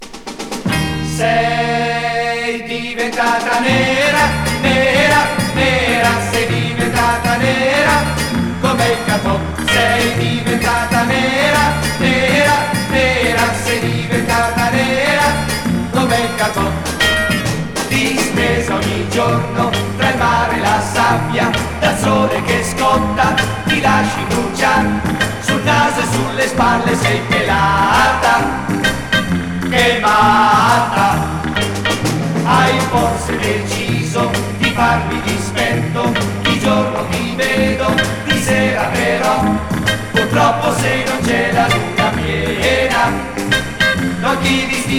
Pop, Vocal　USA　12inchレコード　33rpm　Mono